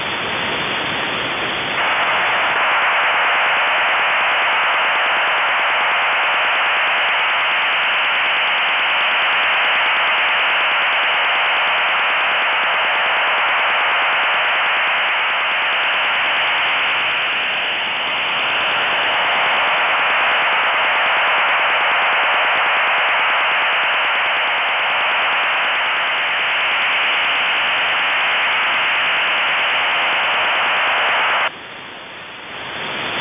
Начало » Записи » Радиоcигналы классифицированные